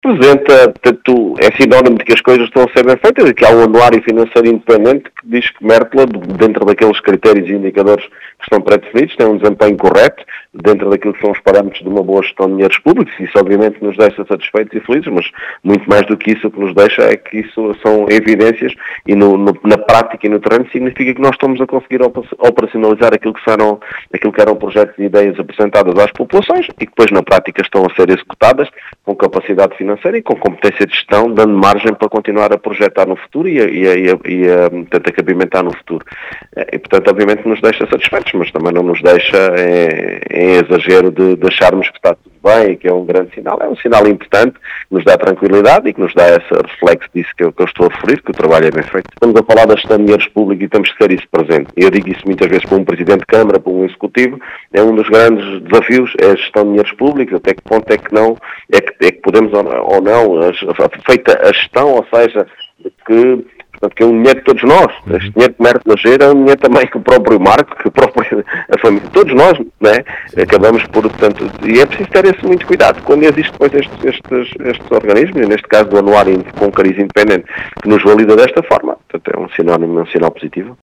É “sinónimo que o trabalho está a ser bem feito”, segundo o presidente da Câmara Municipal de Mértola, Mário Tomé, que fala no “desafio” da gestão dos dinheiros públicos.